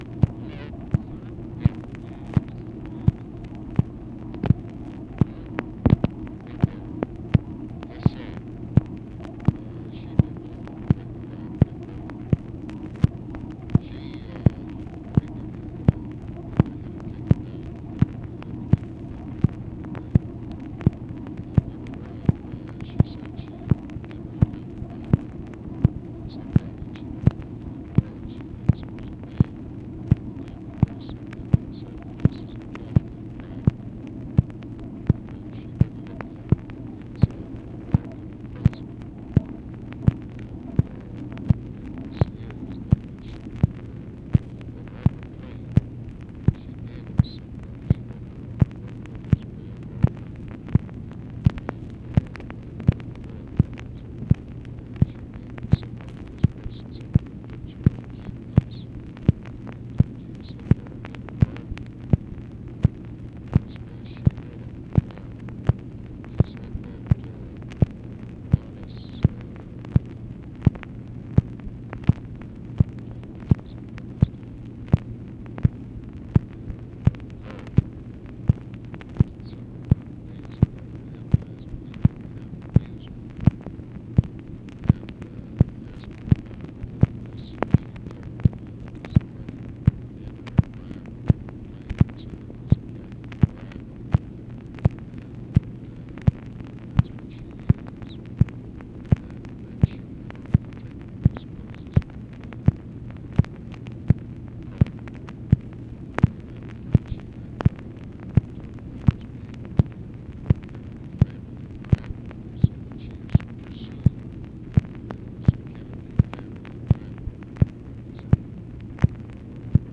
ALMOST INAUDIBLE CONVERSATION APPARENTLY ABOUT ACCOUNT IN WILLIAM MANCHESTER'S UPCOMING BOOK THAT LBJ TOOK OATH OF OFFICE FOLLOWING JFK ASSASSINATION ON KENNEDY FAMILY BIBLE
Conversation with ABE FORTAS and UNIDENTIFIED MALE, August 29, 1966